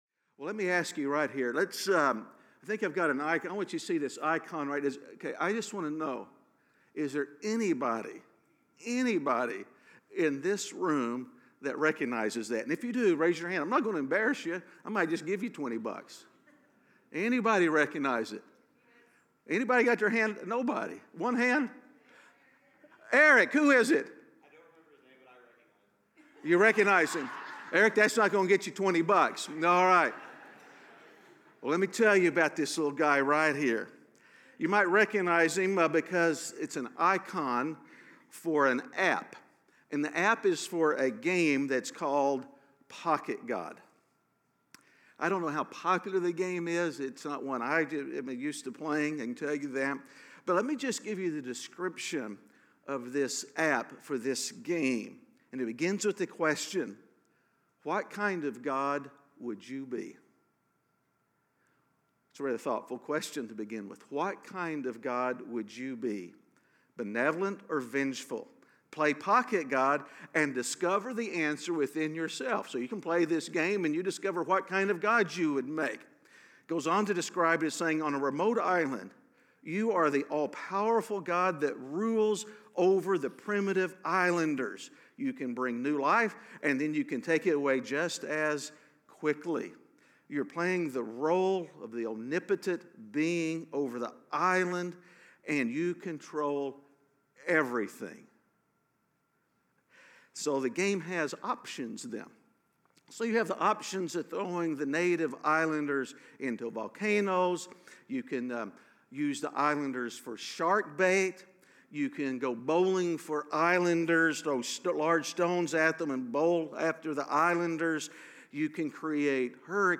A message from the series "The God Who Speaks." The Prophet Micah shows us that God loves to use no-bodies from no-where.